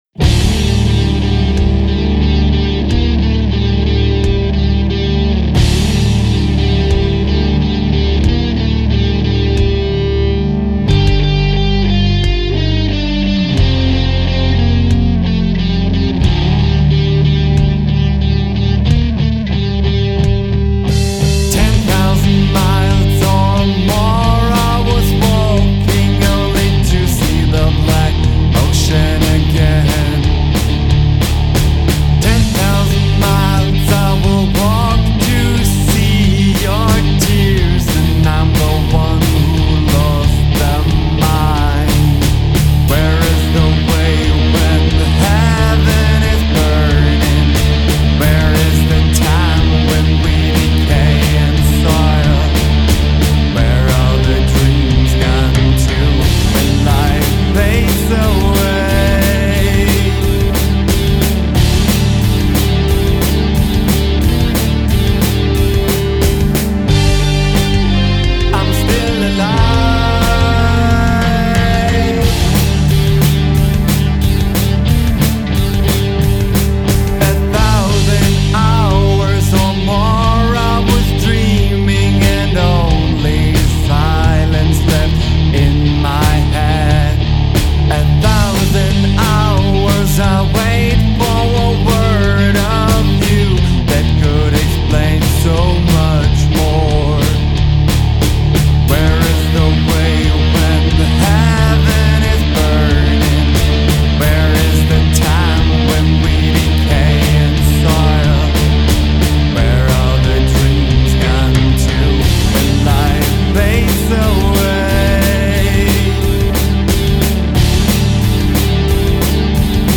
Unsere Musik ordnen wir als Rock mit englischen Texten ein.
Gitarre
Bass